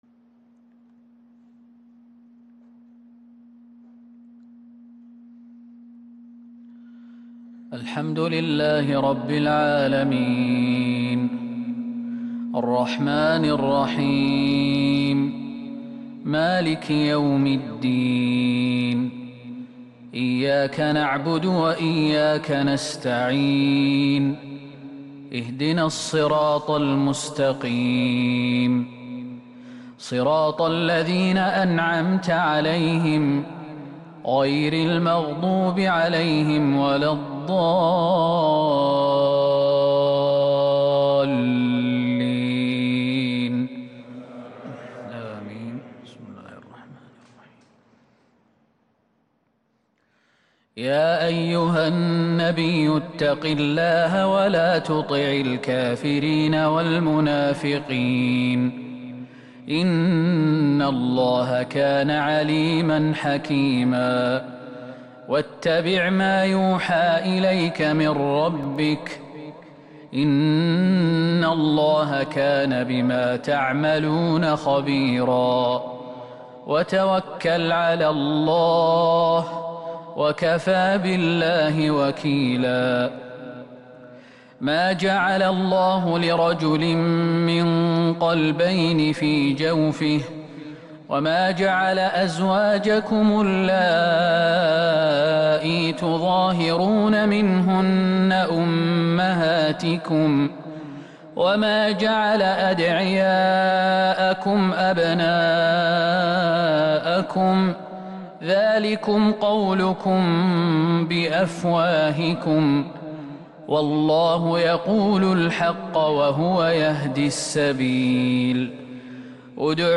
فجر السبت 24 شوال 1442هـ من سورة الأحزاب | Fajr prayer from Surat Al-Ahzab 5-6-2021 > 1442 🕌 > الفروض - تلاوات الحرمين